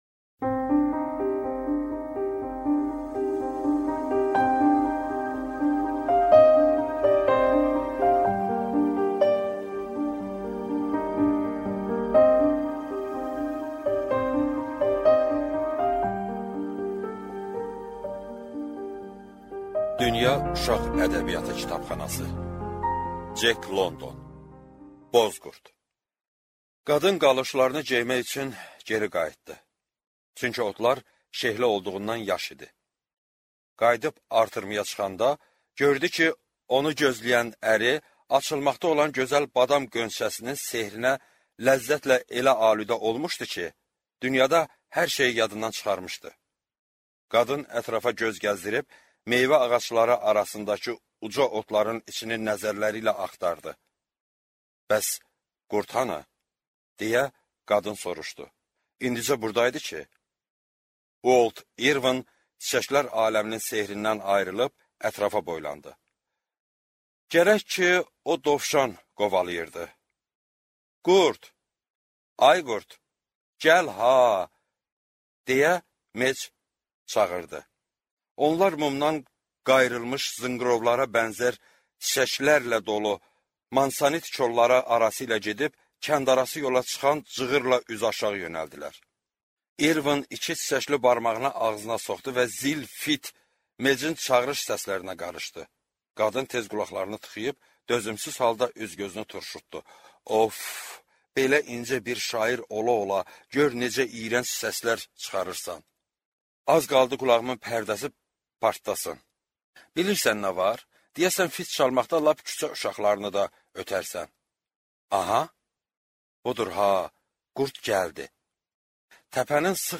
Аудиокнига Boz qurd, Tonqal, Meksikalı | Библиотека аудиокниг